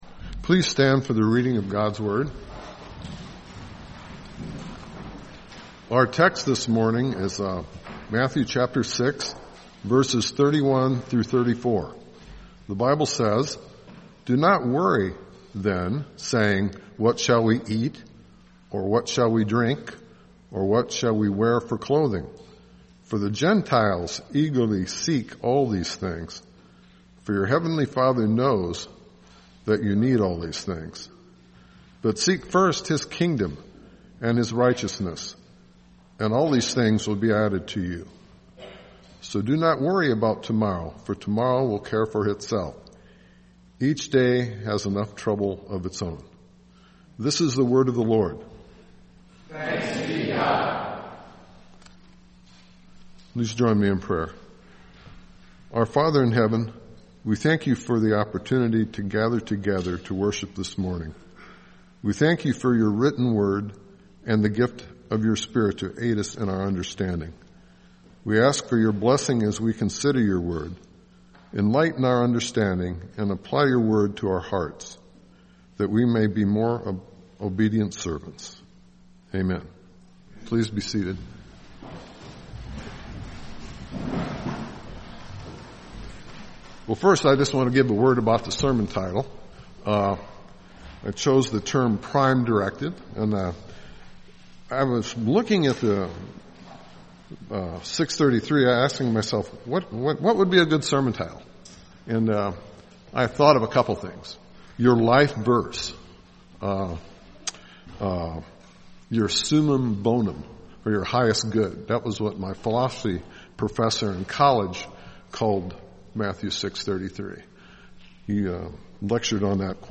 Sermons Jul 29 2018 “Our Prime Directive